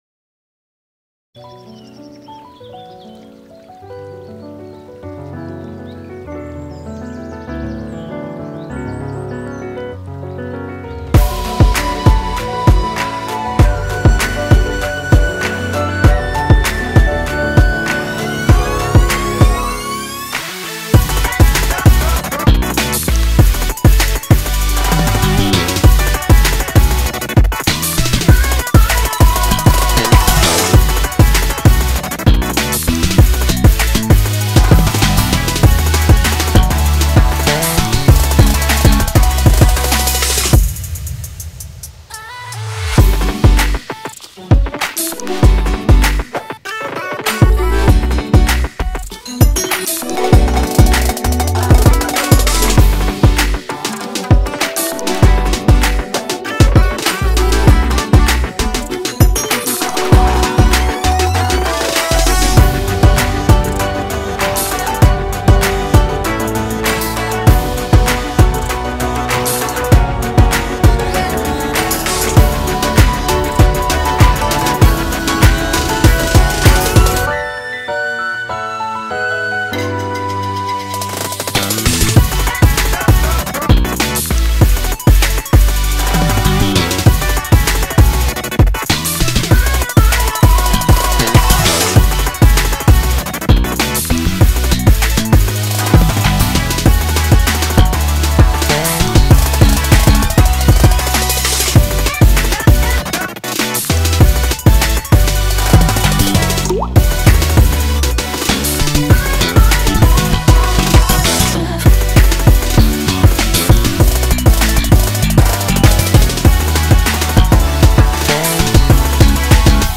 BPM98-196
It's kinda flowy and stuff...